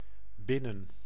Ääntäminen
IPA: ['bɪ.nəⁿ]